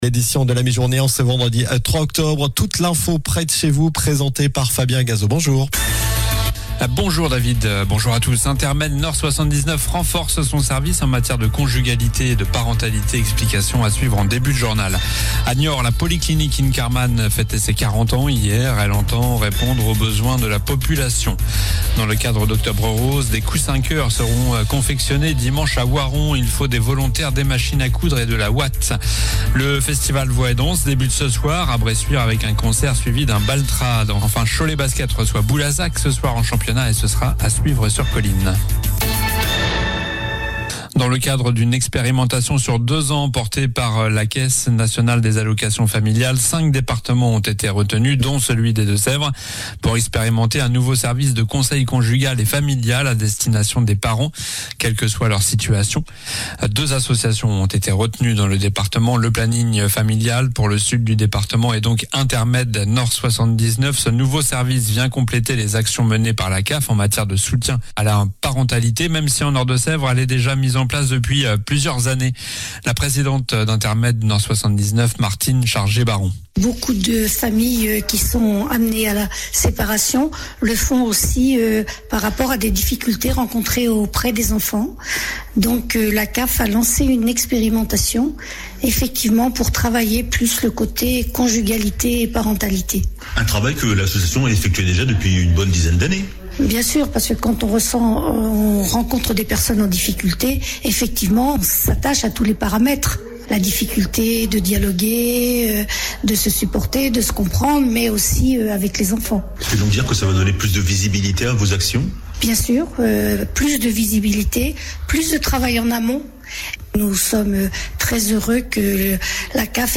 Journal du vendredi 3 octobre (midi)